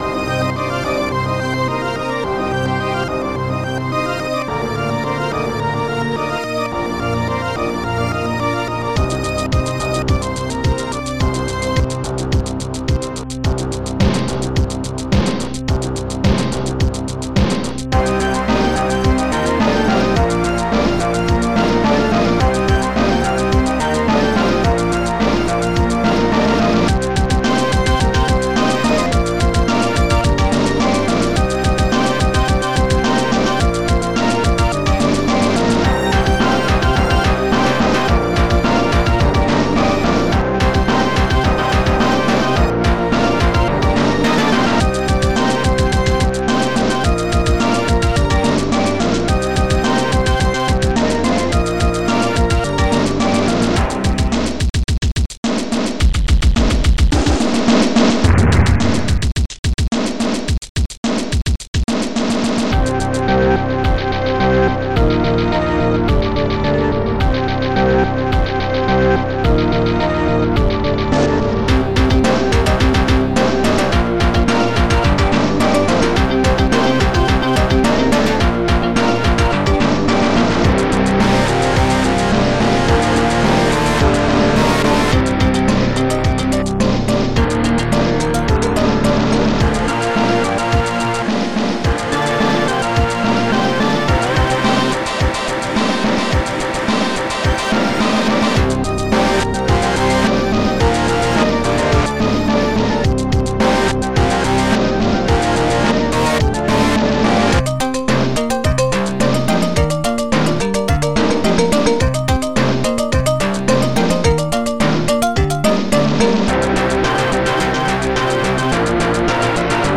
church jarre2 typewriter jarre4 snare14 bassdrum17 st-01:dx7bass st-01:bonus st-01:distgit2 st-01:distgit3